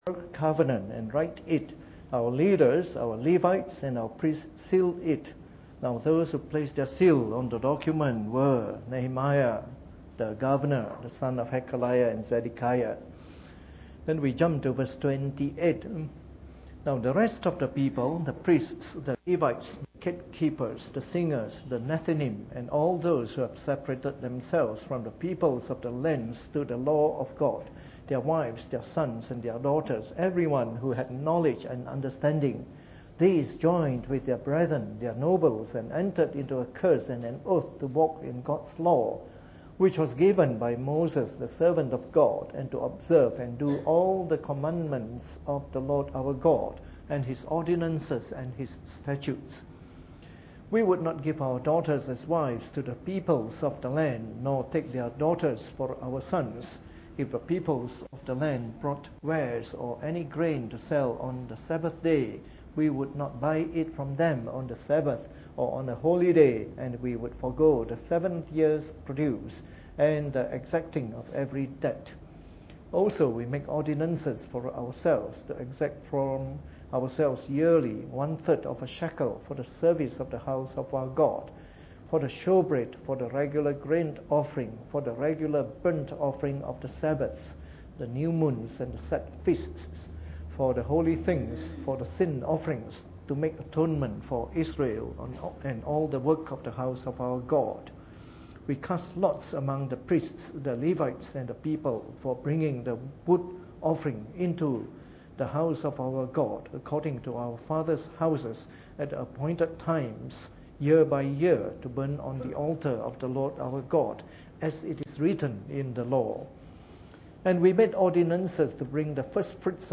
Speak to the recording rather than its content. Preached on the 9th of July 2014 during the Bible Study, from our series of talks on the Book of Nehemiah.